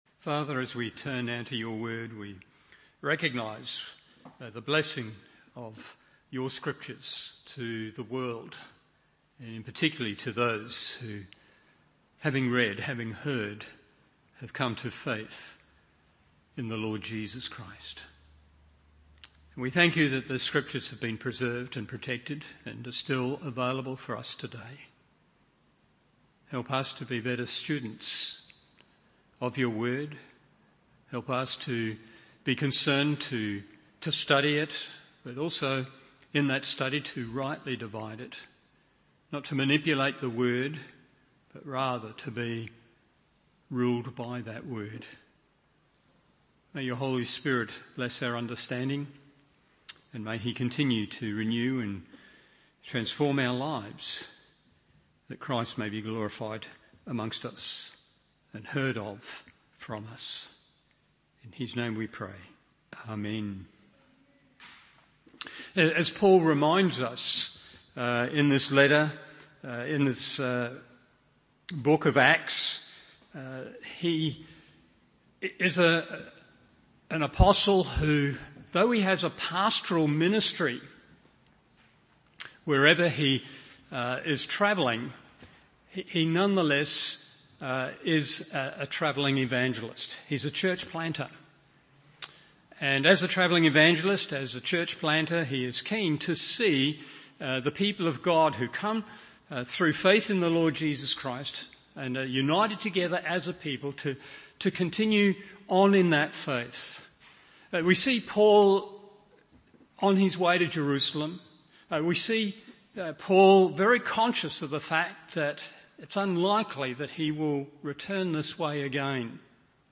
Evening Service Acts 20:28-38 1.